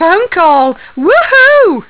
Cŕŕn Phone Call juhuuu 0:01